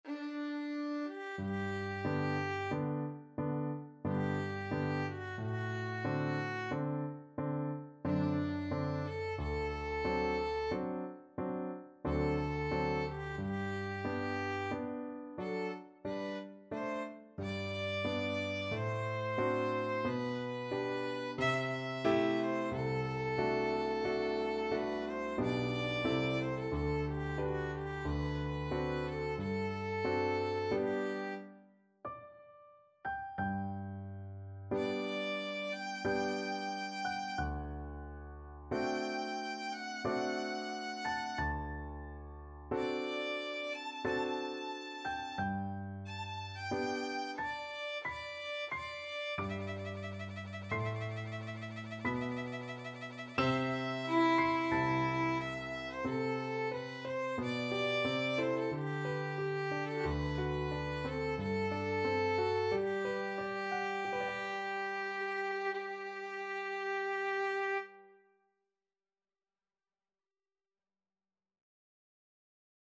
Adagio =45
3/4 (View more 3/4 Music)
D5-A6
Classical (View more Classical Violin Music)